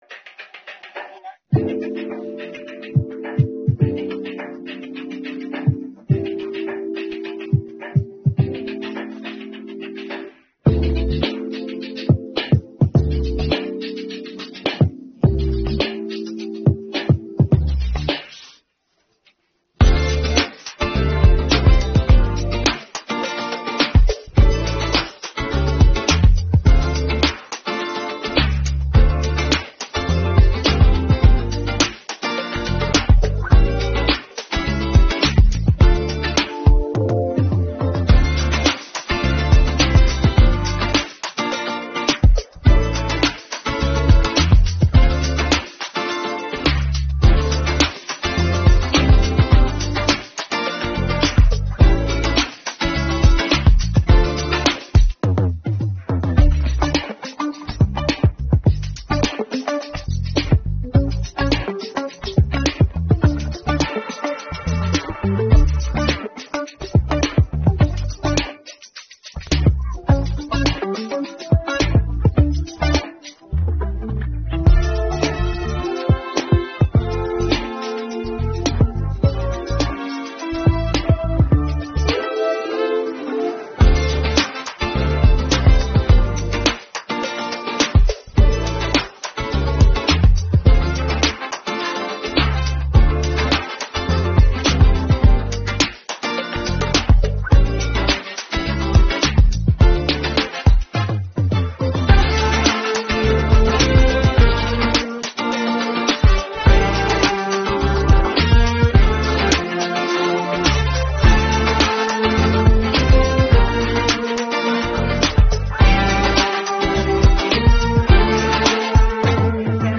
Українські хіти караоке